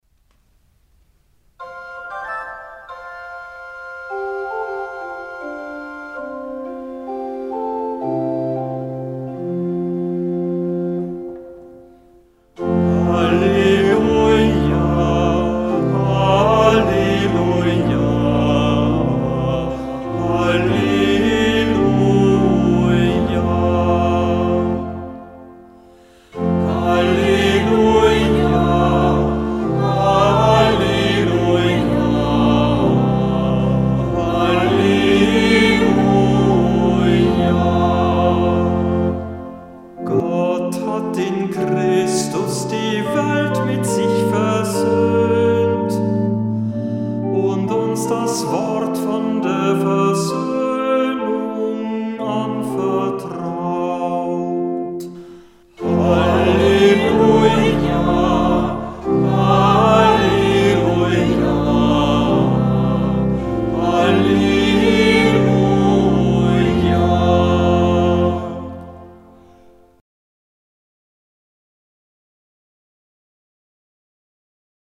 Hörbeispiele aus dem Halleluja-Büchlein